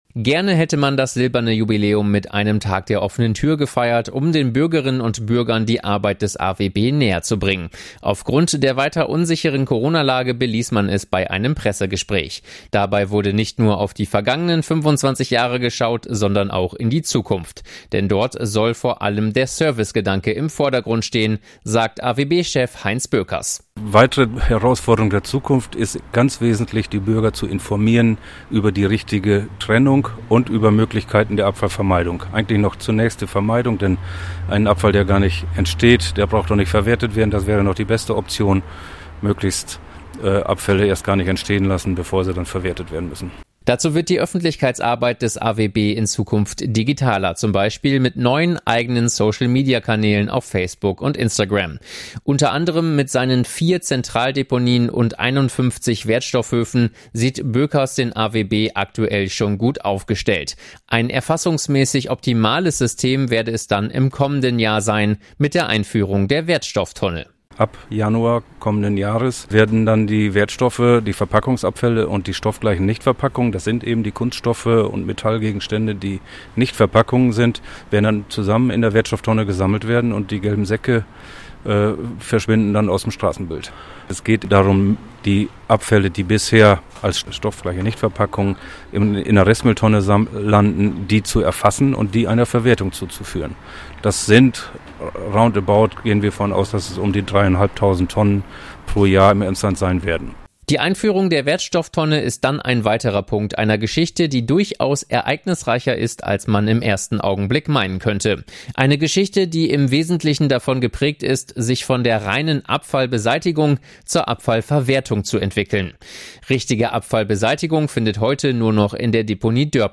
Anlässlich des Jubiläums gab es bei einem Pressegespräch einen kleinen Rückblick aber auch einen Ausblick in die Zukunft des AWB. EVW-Reporter